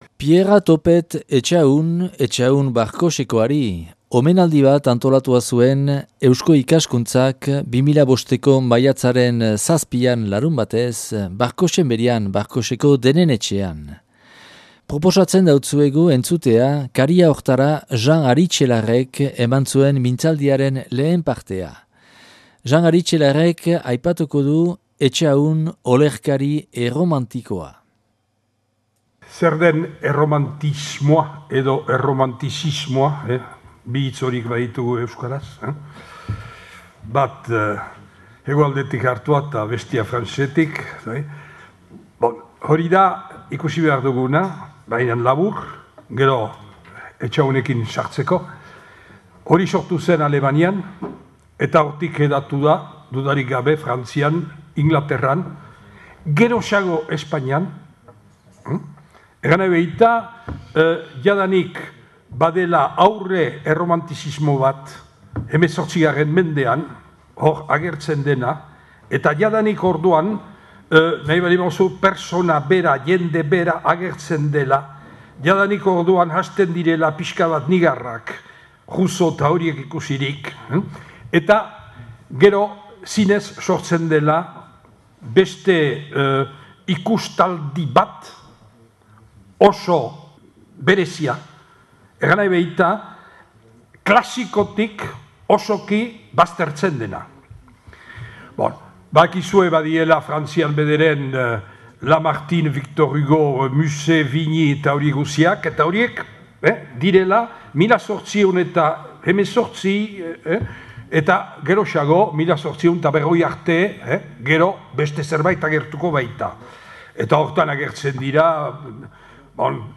Pierra Topet Etxahun omenaldia - Barkotxen 2005. Maiatzaren 7an